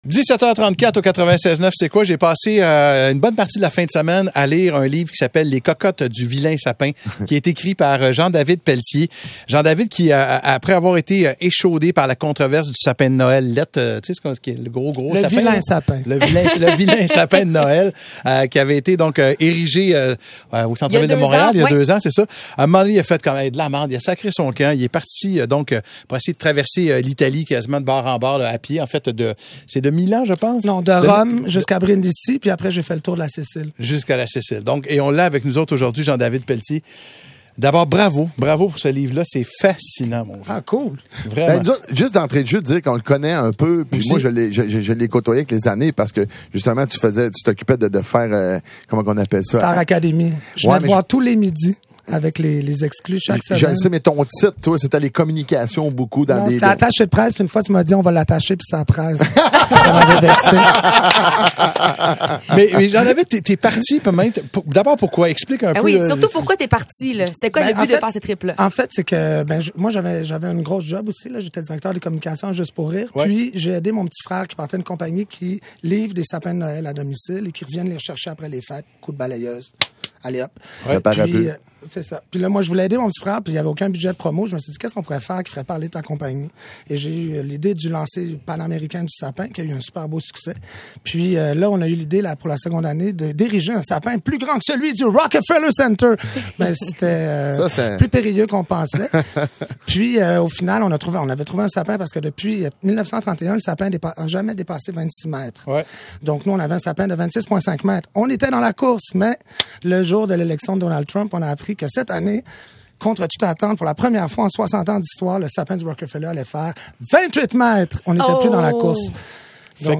Les cocottes du Vilain Sapin - Entrevue